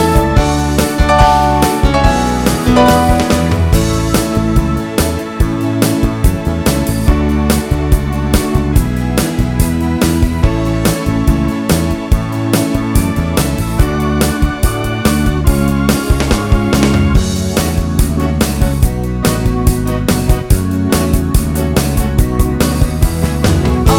No Drums Pop (1970s) 3:13 Buy £1.50